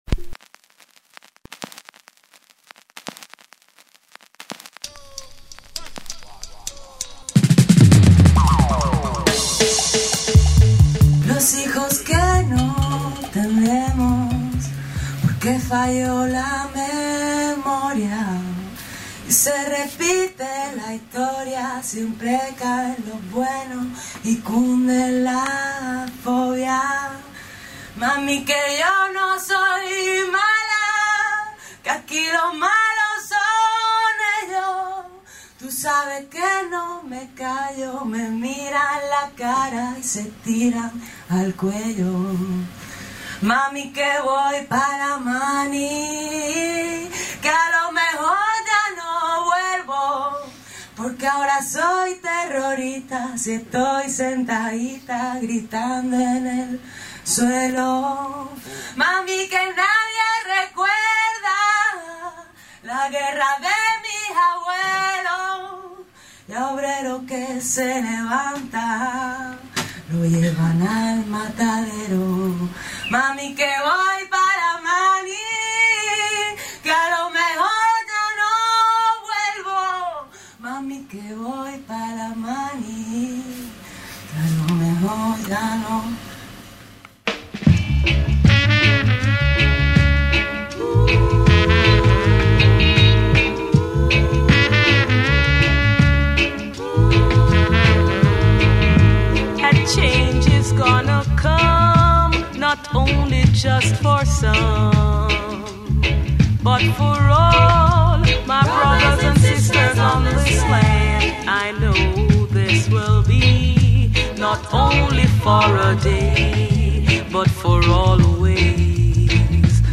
reggae y dancehall